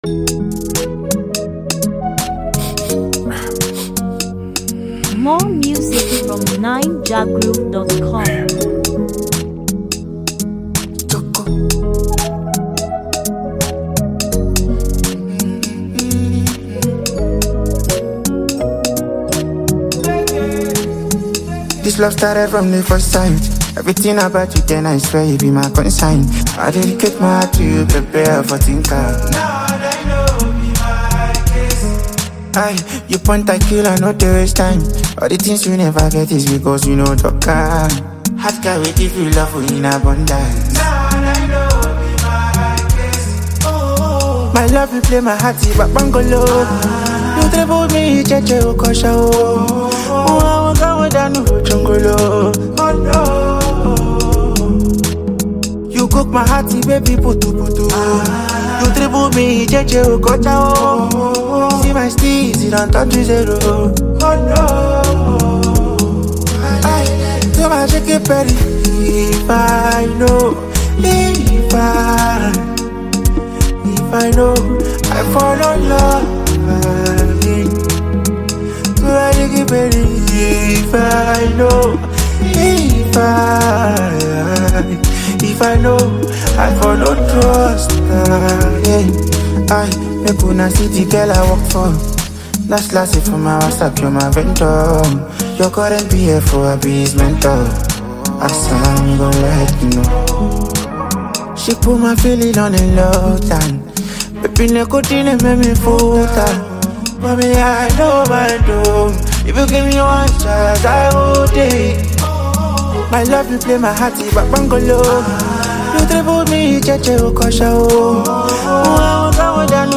Latest, Naija-music